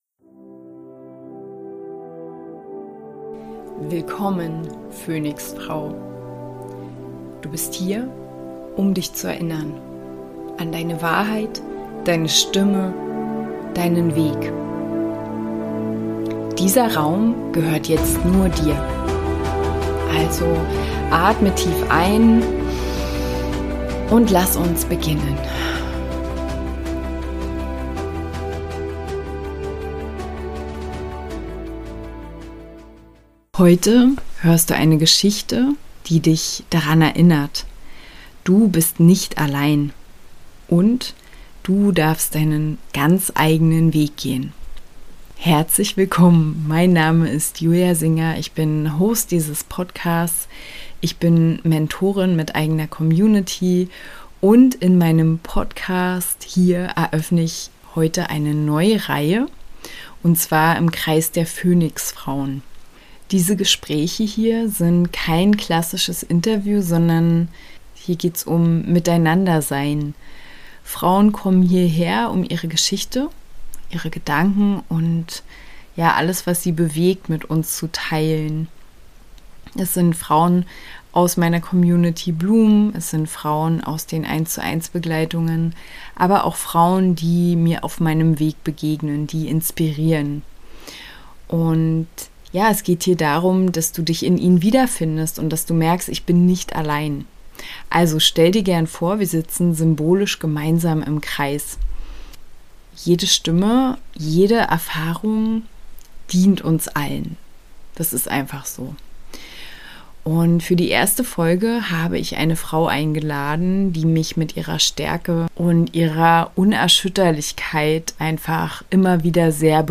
Es sind keine klassischen Interviews – sondern Gespräche von Frau zu Frau, die dich mitnehmen in das echte Leben.